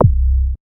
MoogPuls 002.WAV